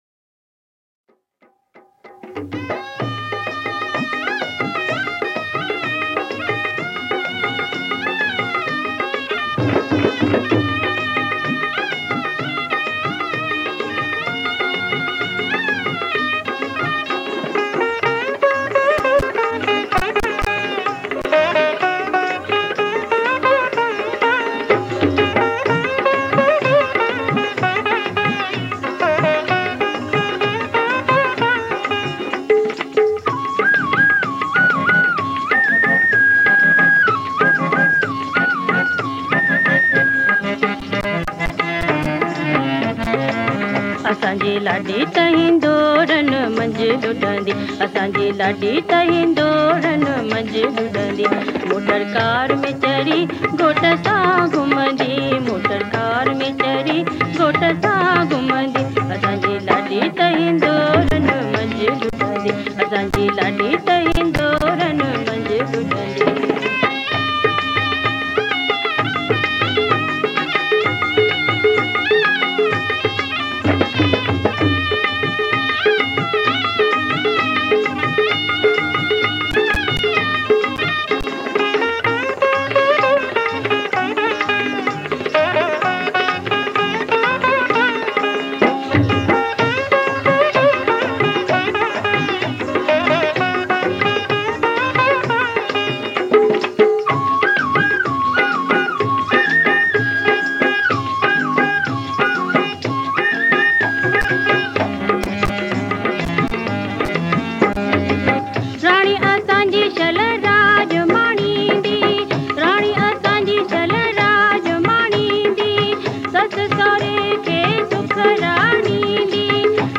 Sindhi Jhulelal Geet, Lada, Kalam, Ghazal & Bhajans